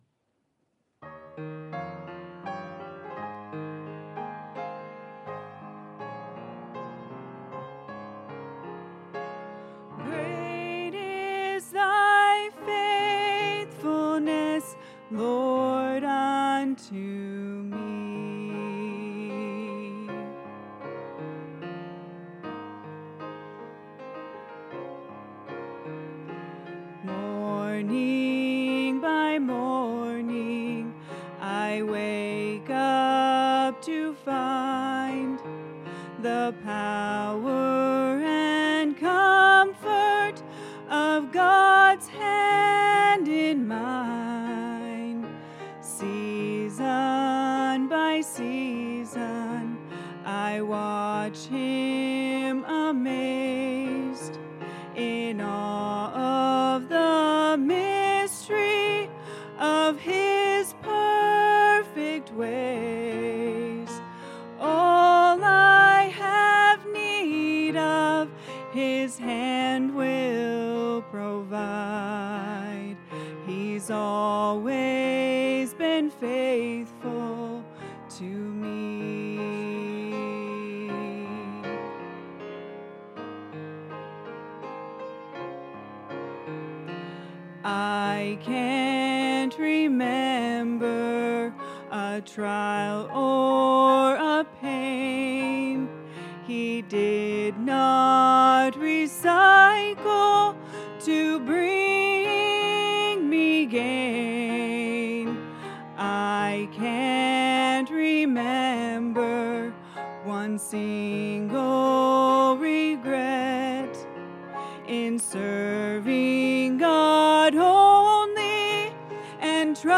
The Upper Room | Sunday AM